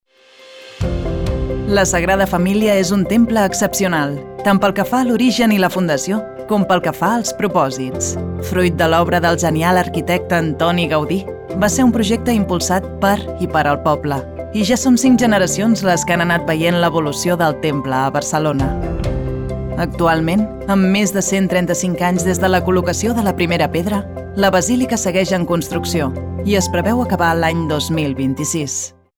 Warm, Zacht, Natuurlijk, Vriendelijk, Jong
Corporate